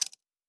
Metal Tools 01.wav